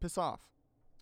Voice Lines / Dismissive
piss off.wav